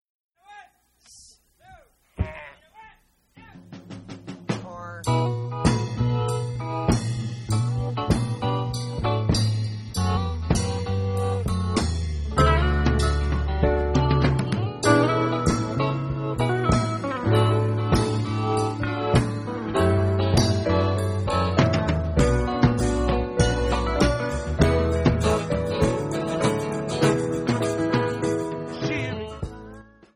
Jamband
Psychedelic
Rock